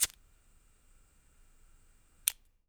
LIGHTER 5 -S.WAV